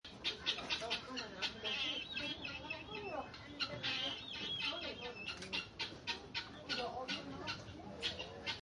Pintada o Gallina de Guinea (Numida meleagris)
Comportamiento : Ruidosa, se mueve en grupos grandes.